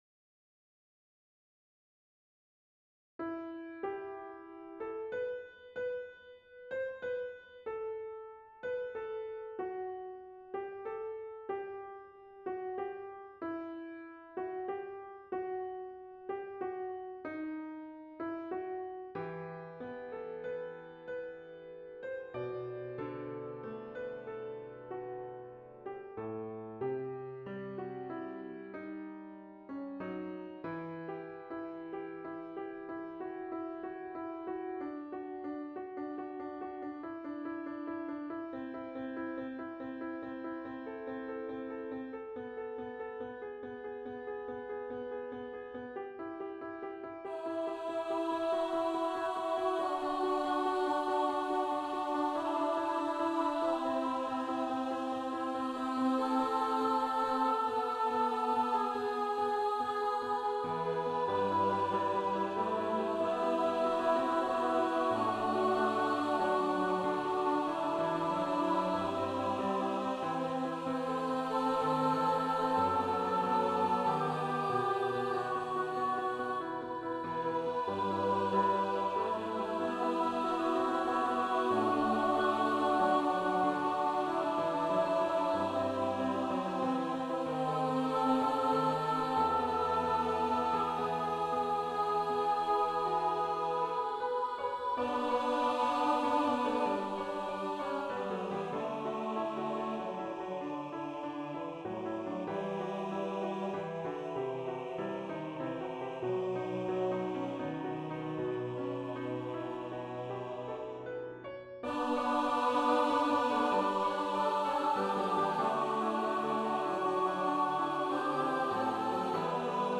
Voicing/Instrumentation: SATB , Piano Solo